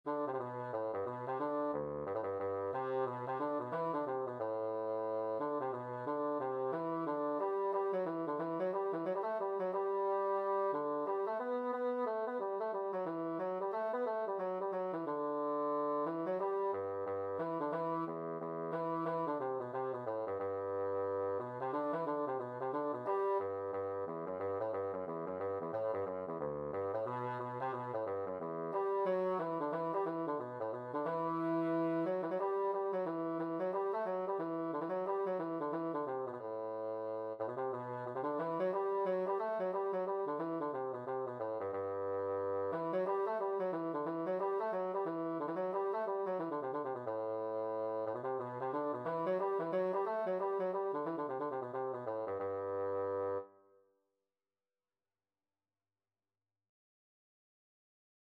Free Sheet music for Bassoon
4/4 (View more 4/4 Music)
G major (Sounding Pitch) (View more G major Music for Bassoon )
Bassoon  (View more Easy Bassoon Music)
Traditional (View more Traditional Bassoon Music)